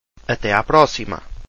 Até a próxima   Atay a prossima